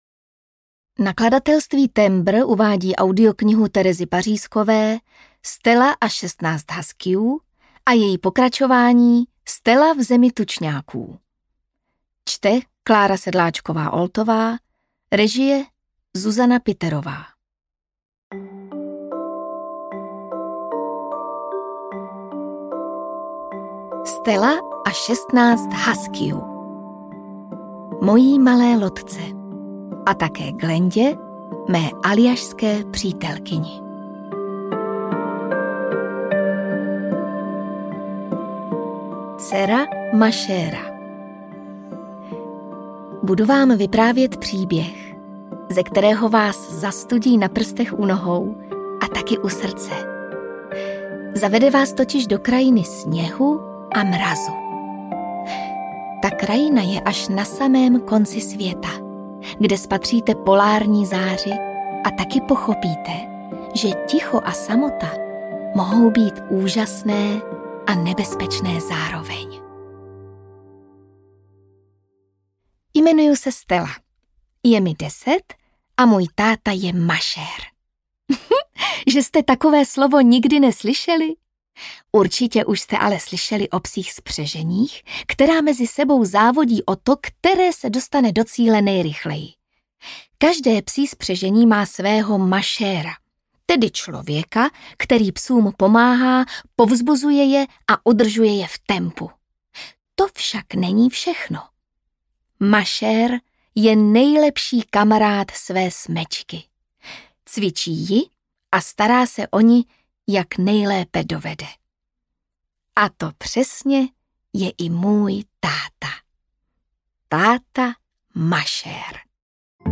Stela a 16 huskyů audiokniha
Ukázka z knihy